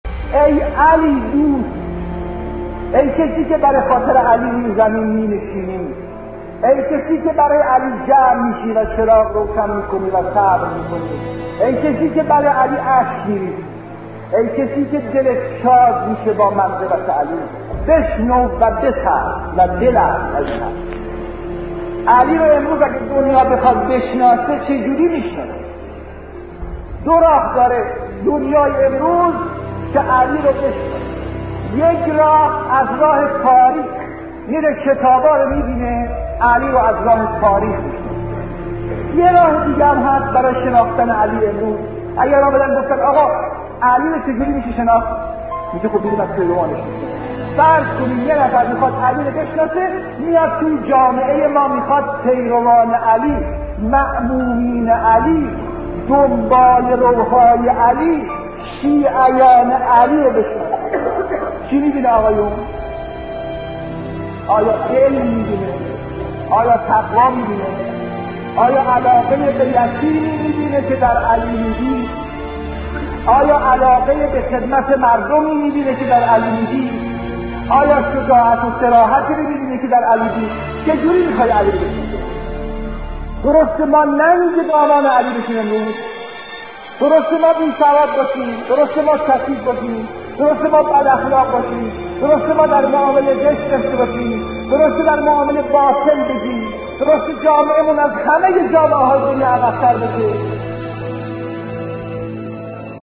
قطعه صوتی از امام موسی صدر درباره شناخت امیرالمومنین علیه السلام